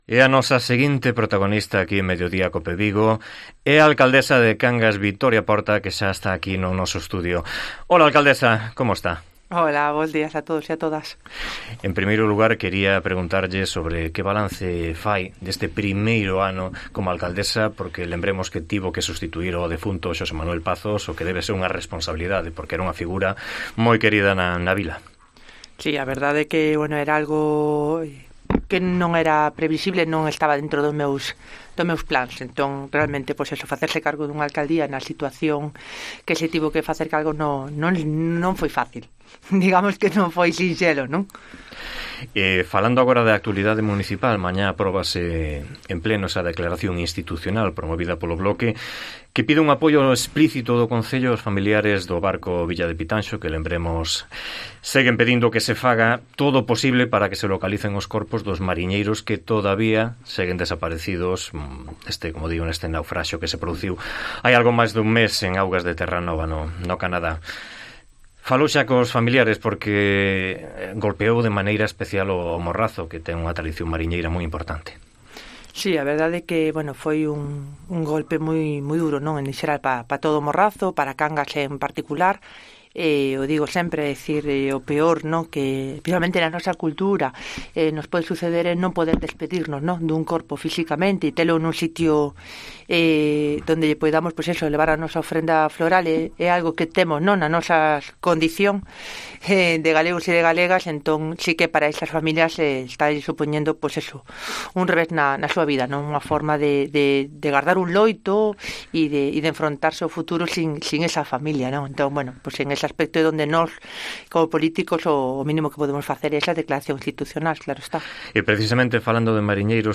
Entrevista con Victoria Portas, alcaldesa de Cangas do Morrazo